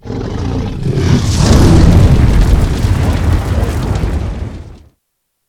firebreath2.ogg